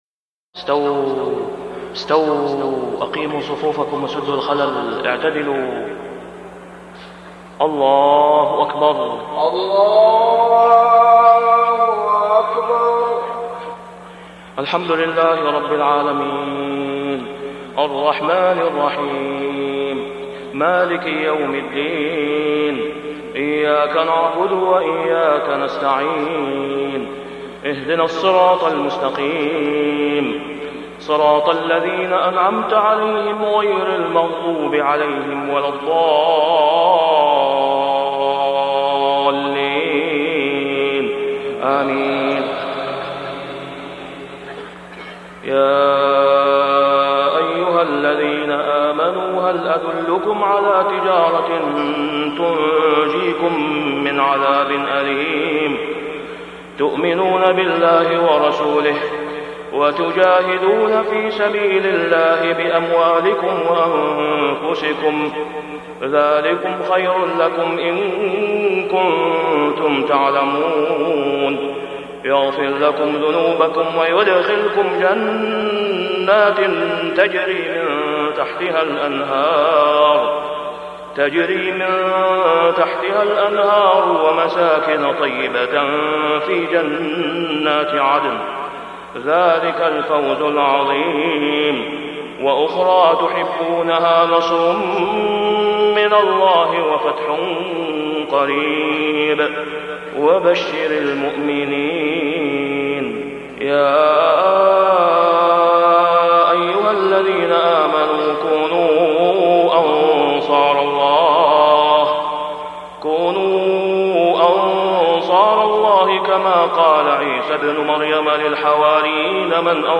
صلاة العشاء ( العام مجهول ) | ماتيسر من سورة الصف 10-14 | > 1420 🕋 > الفروض - تلاوات الحرمين